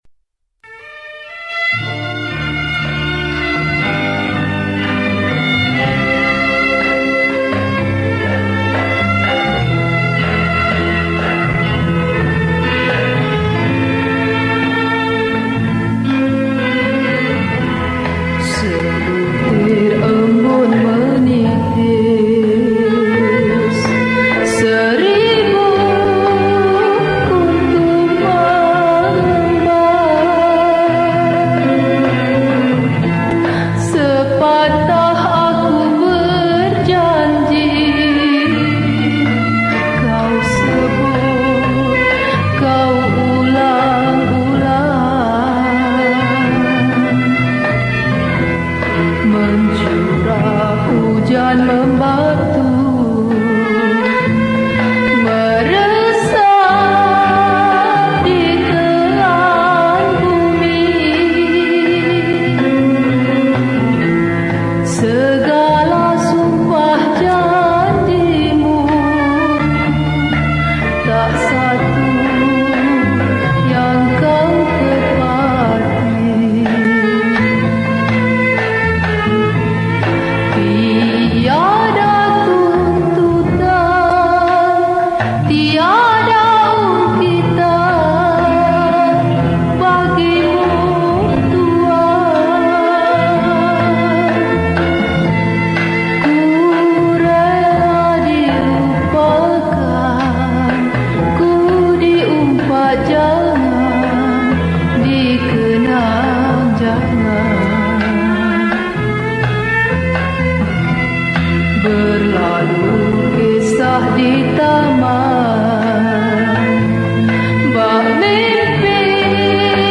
Skor Angklung